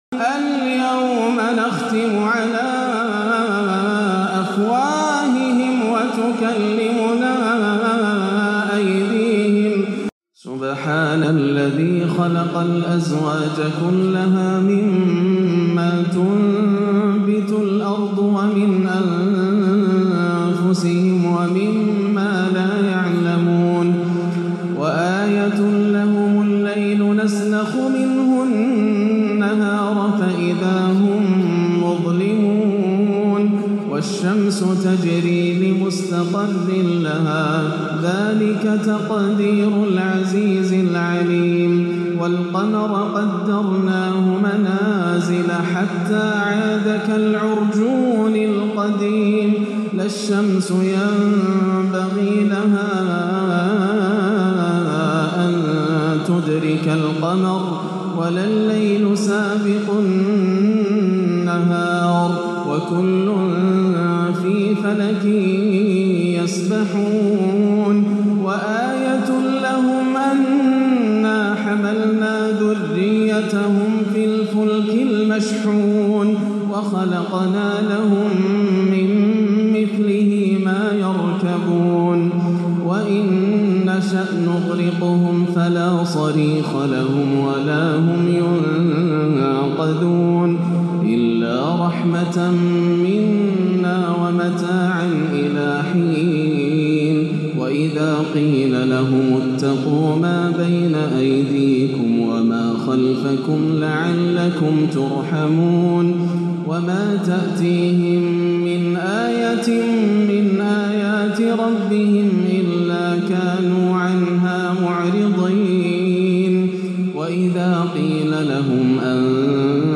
(الْيَوْمَ نَخْتِمُ عَلَىٰ أَفْوَاهِهِمْ) تلاوة عراقية باكية لما تيسر من سورة يس - الخميس 22-11 > عام 1437 > الفروض - تلاوات ياسر الدوسري